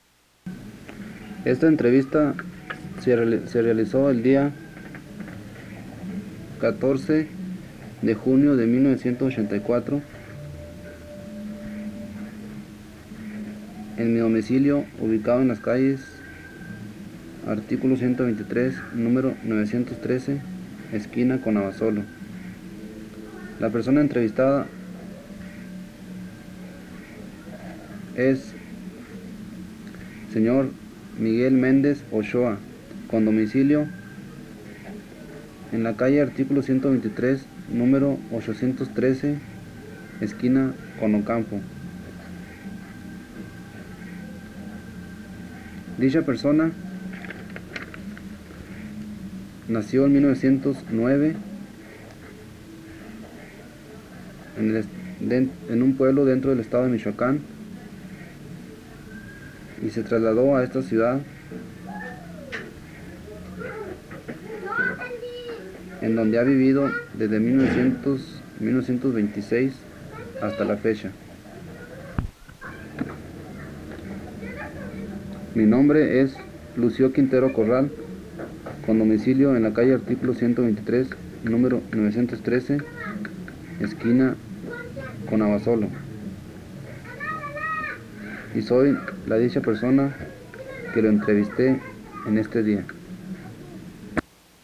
Entrevistado
Entrevistador